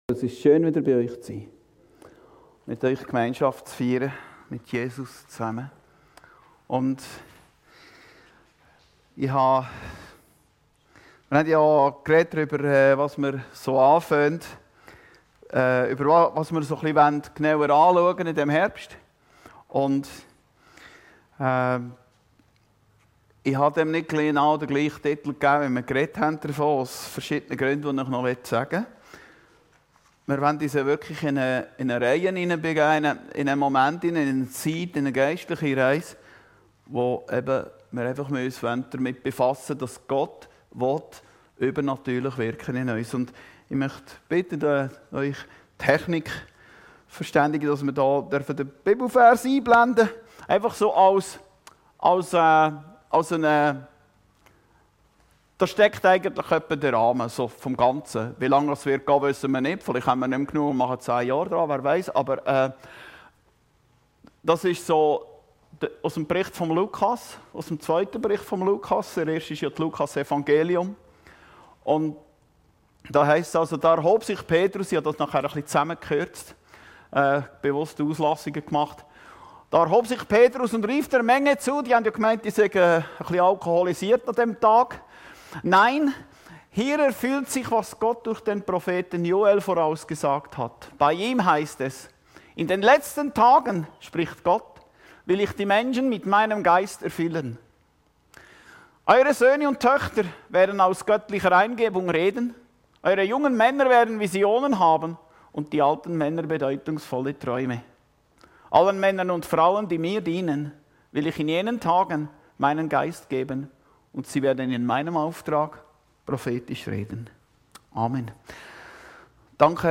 Predigten Heilsarmee Aargau Süd – GOTT WILL IN UNS ÜBERNATÜRLICH WIRKEN